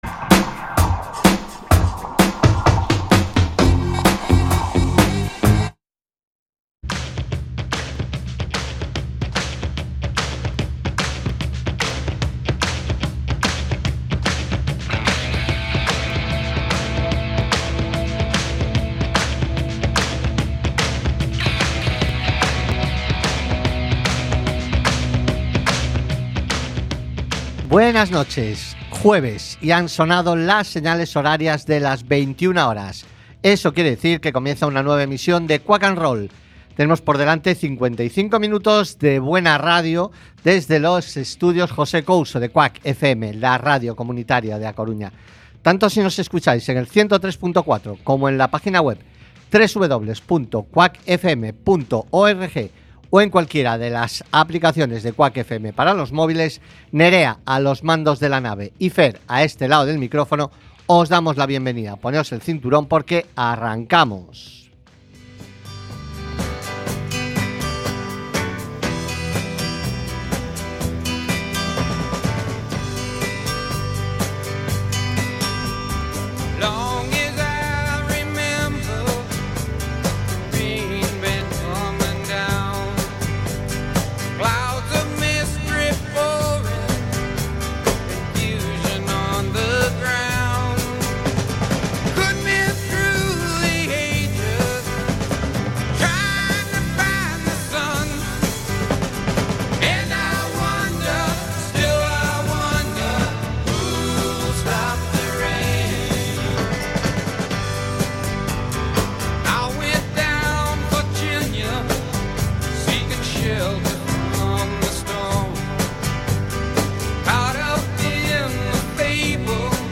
Programa musical que huye de las radioformulas y en el que podreis escuchar diversidad de generos... Rock , Blues , Country, Soul , Folk , Punk , Heavy Metal , AOR...